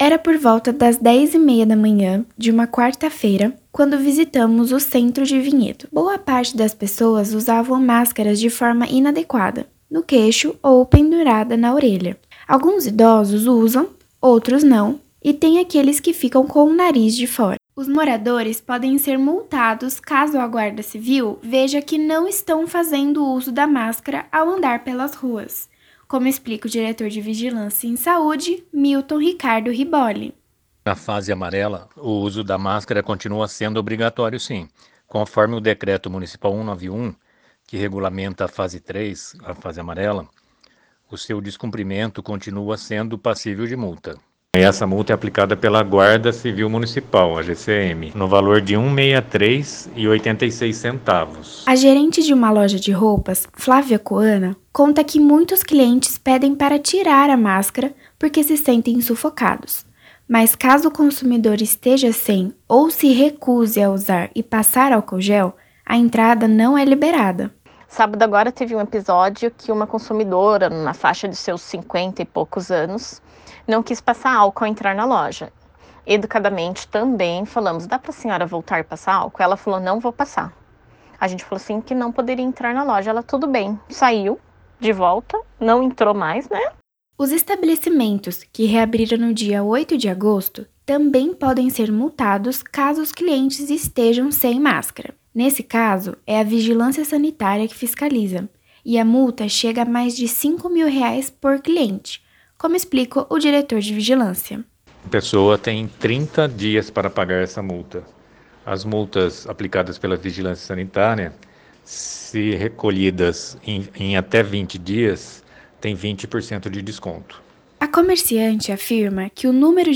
Abaixo, acesso ao arquivo de áudio da reportagem publicada no Giro RMC.